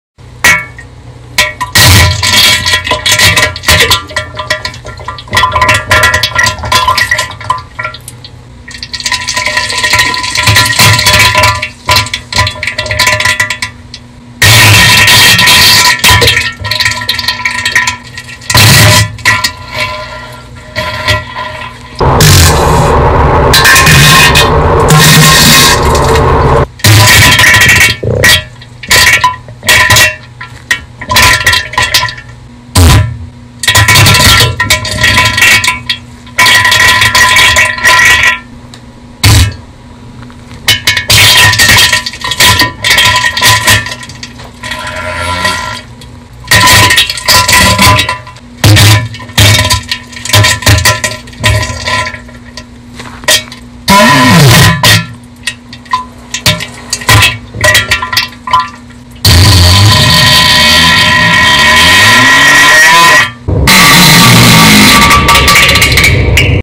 diarrhée fulgurante